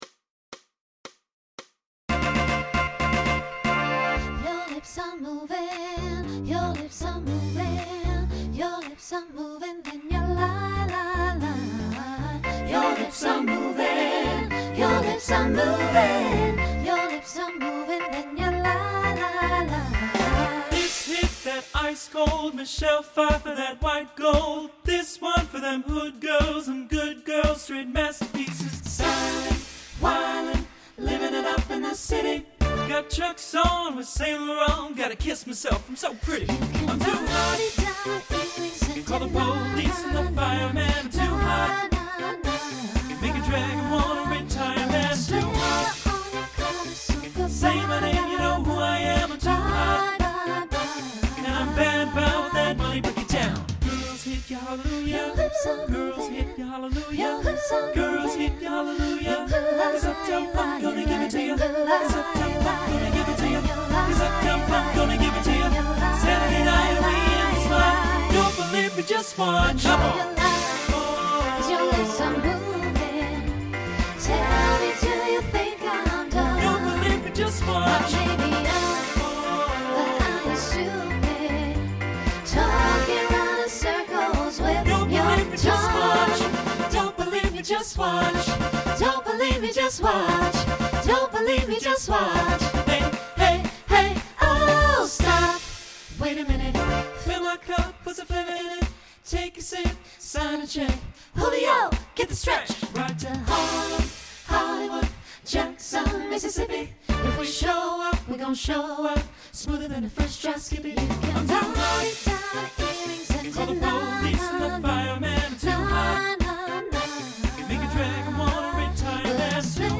SATB Instrumental combo
Pop/Dance